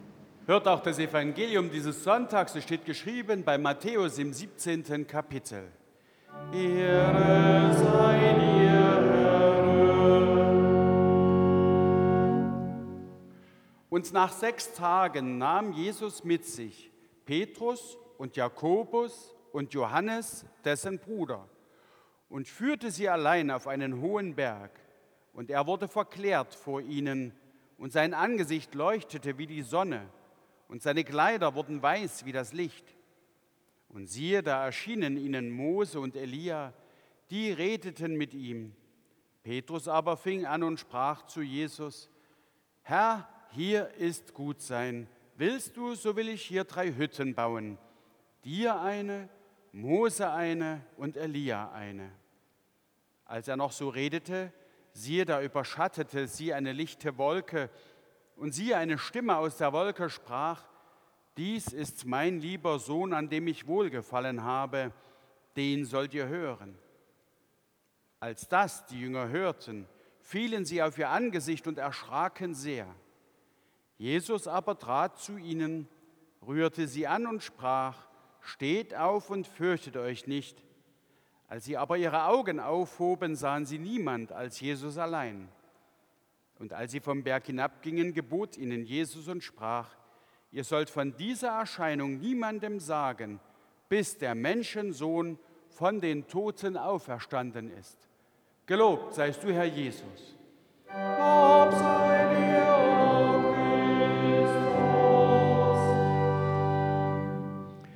7. Evangeliumslesung aus Matthäus 17,1-9 Evangelisch-Lutherische St. Johannesgemeinde Zwickau-Planitz
Audiomitschnitt unseres Gottesdienstes vom Letzten Sonntag nach Epipanias 2026.